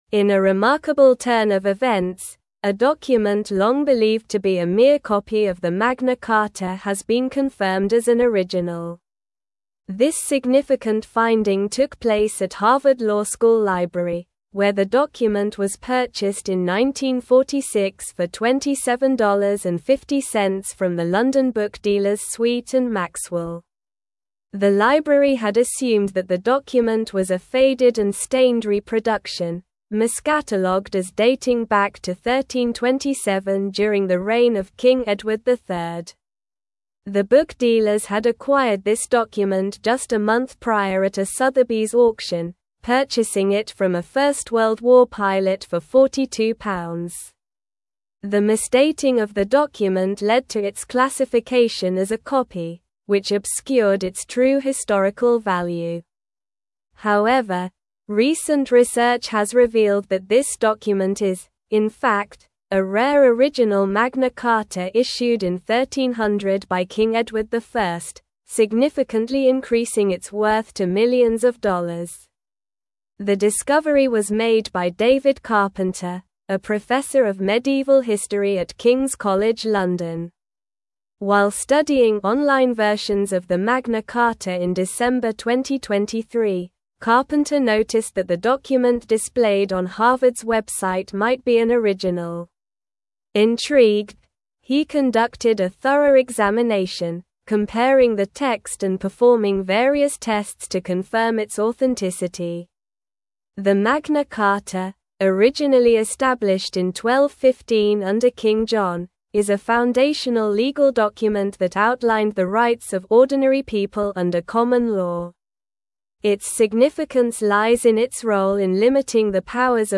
Slow
English-Newsroom-Advanced-SLOW-Reading-Harvard-Library-Confirms-Original-Magna-Carta-Discovery.mp3